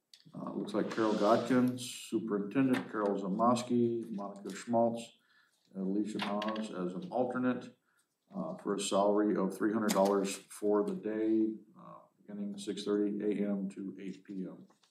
The election board and salary were approved.  Mayor Cox –